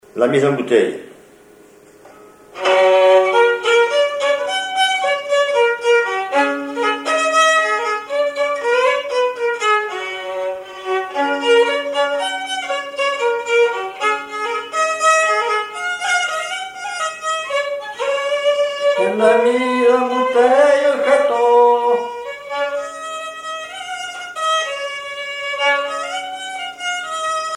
violoneux, violon
danse : marche
Pièce musicale inédite